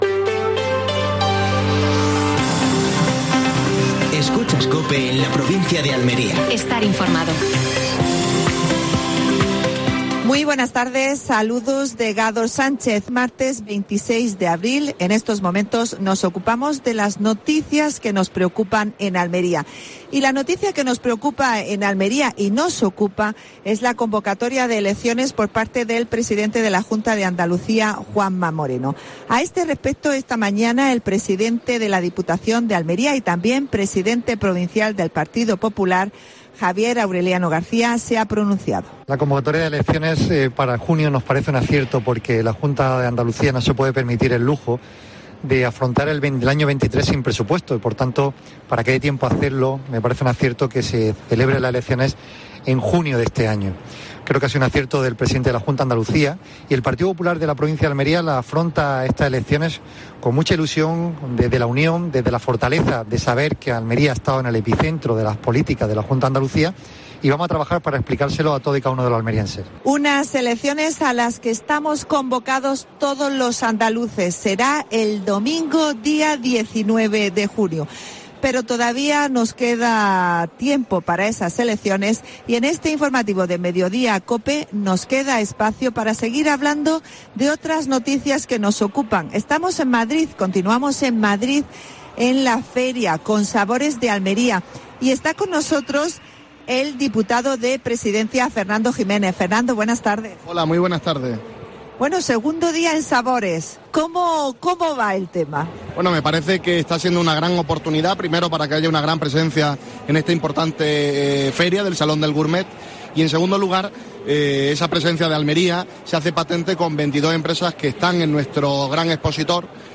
Entrevista a Fernando Giménez (diputado provincial de Almería). Triunfo de la UDA ante el Sporting (1-0).